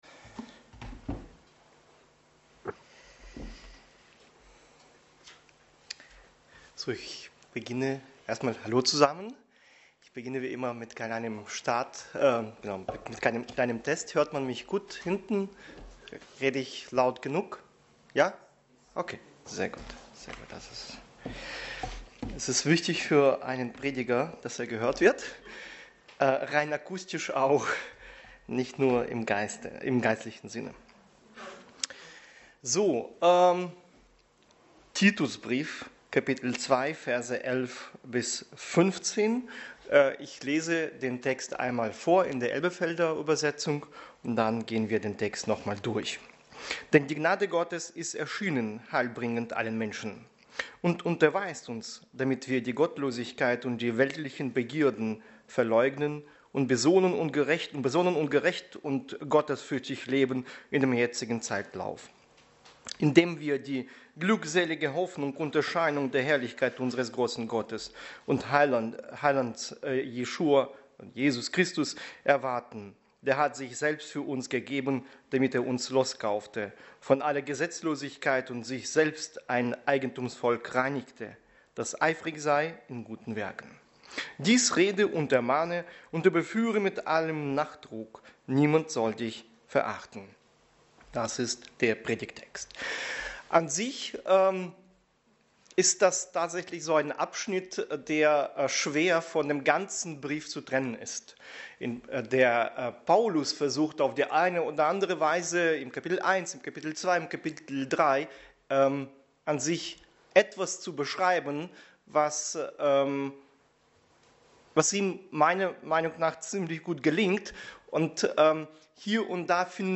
Dienstart: Predigt Themen: Heiligung , Kraft , Offenbarung , Veränderung